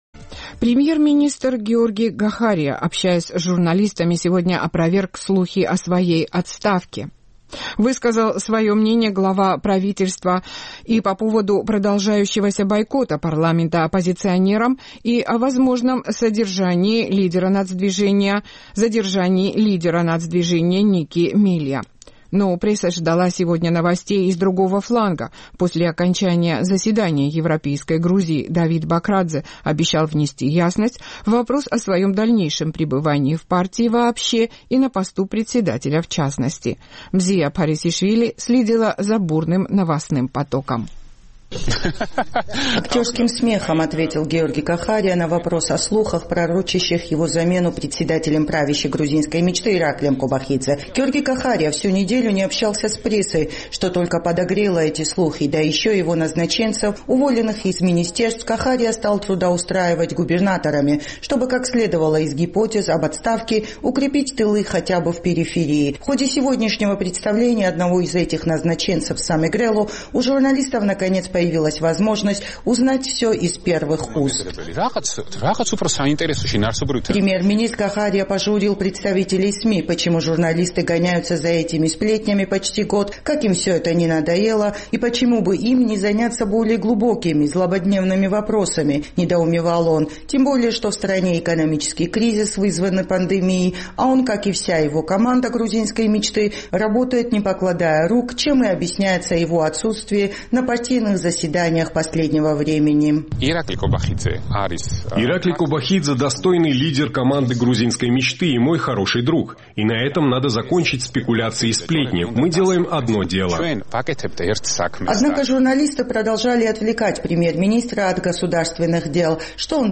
Премьер-министр Георгий Гахария, общаясь с журналистами, опроверг слухи о своей отставке. Высказал свое мнение глава правительства и по поводу продолжающегося бойкота парламента оппозиционерам, и о возможном задержании лидера «Нацдвижения» Ники Мелия.